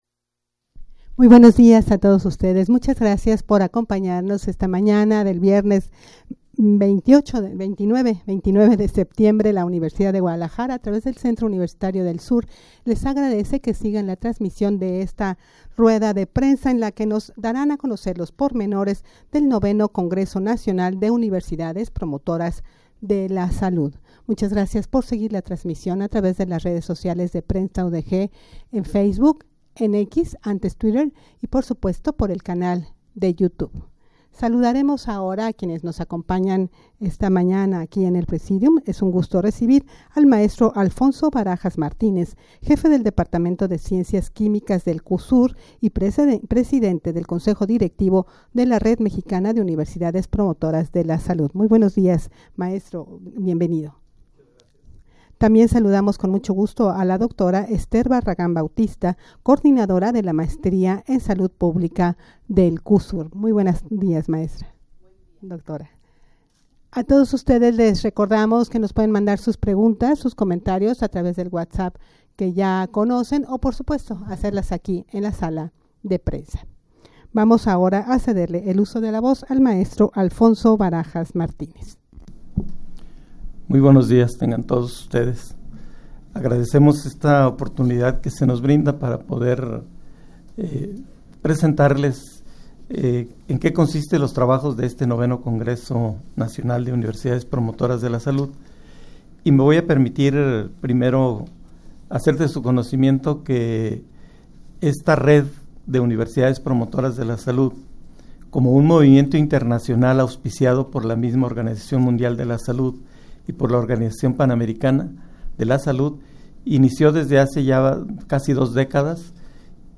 rueda-de-prensa-para-anunciar-el-ix-congreso-nacional-de-universidades-promotoras-de-la-salud.mp3